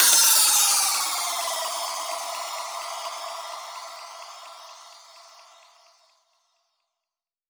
VES2 FX Downlifter
VES2 FX Downlifter 25.wav